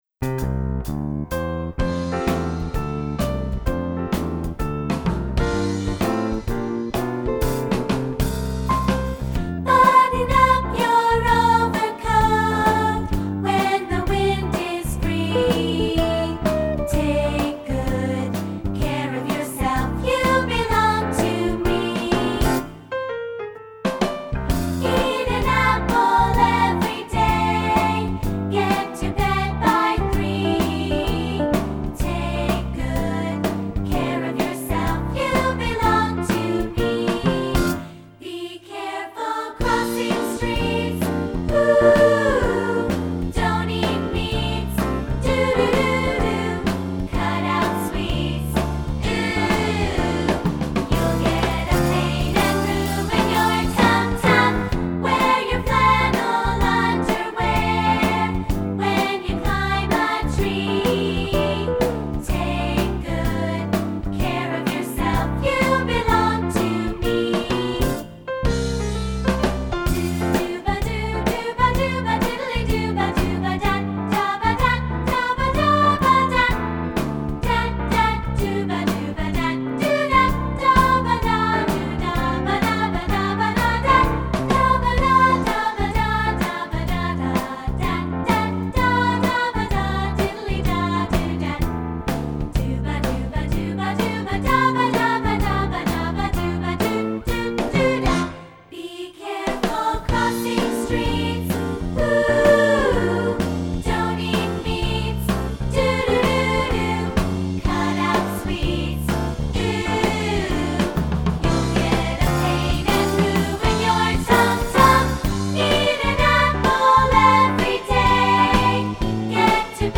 Gattung: für zwei Klarinetten, Bassklarinette